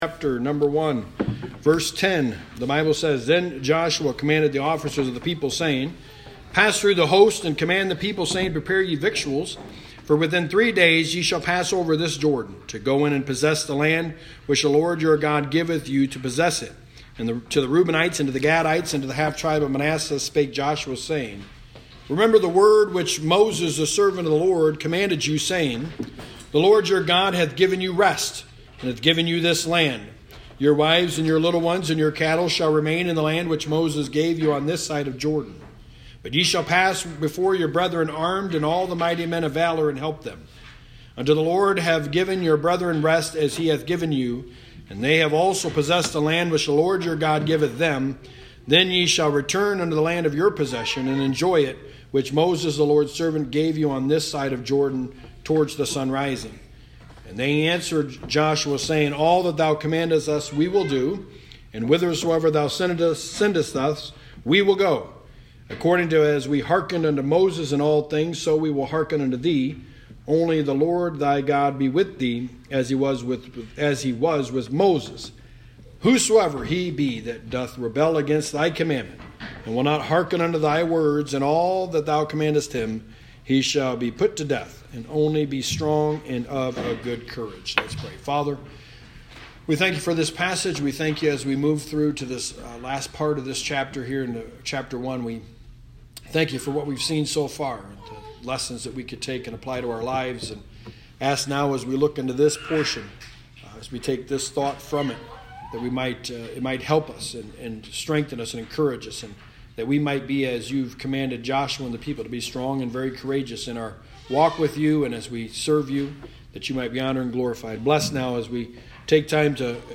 The Book of Joshua: Sermon 7 – Joshua 1:10-18
Service Type: Sunday Morning